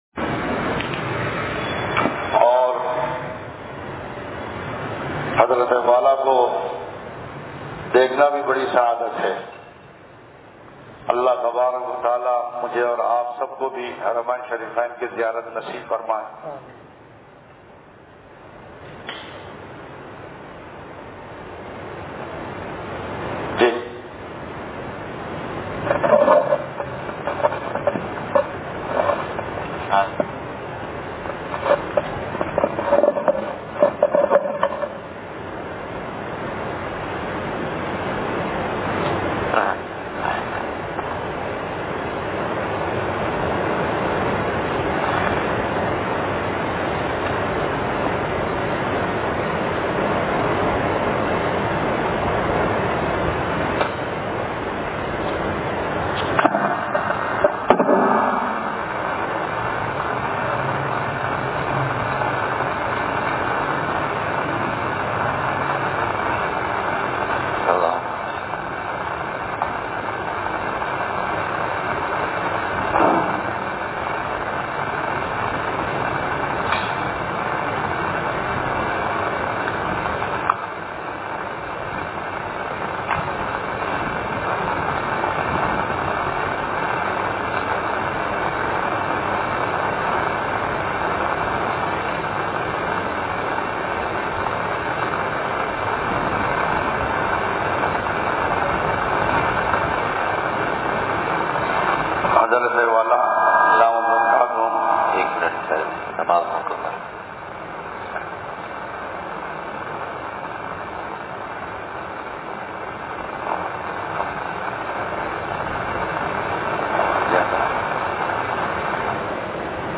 بیان – انصاریہ مسجد لانڈھی